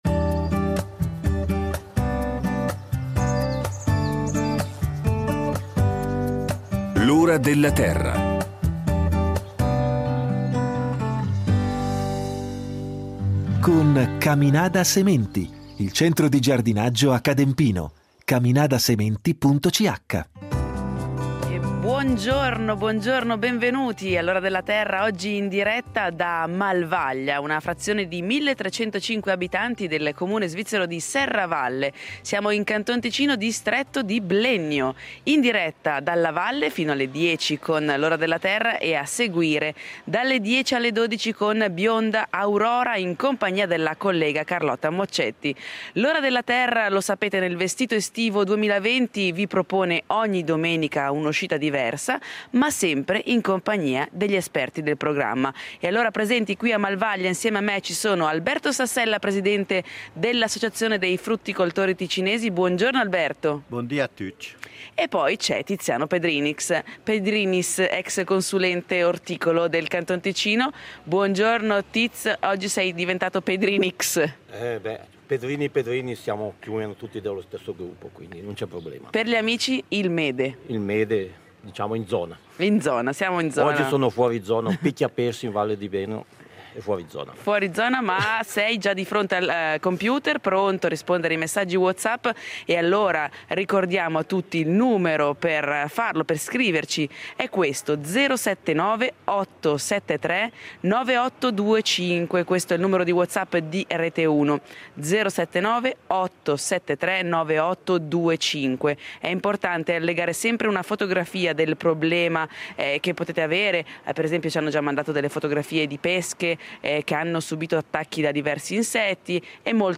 L’ora della terra e Bionda Aurora in diretta da Malvaglia.